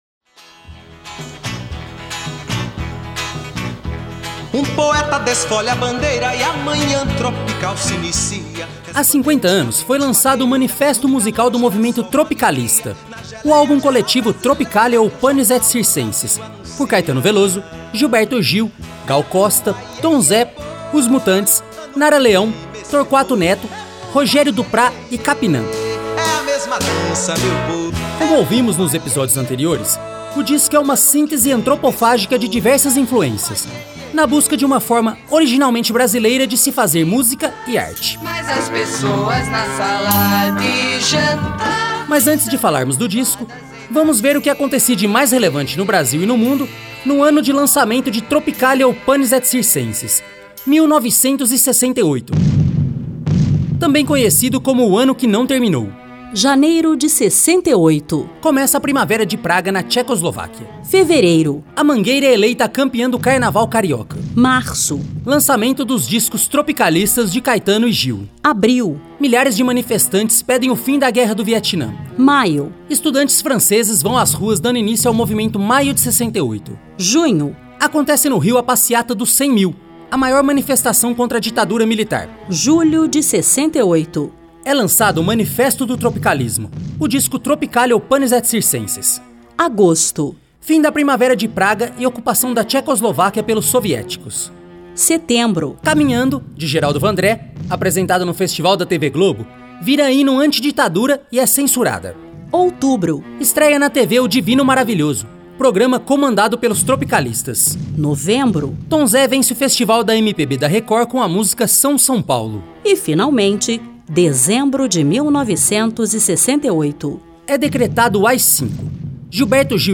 reportagem especial da Rádio Senado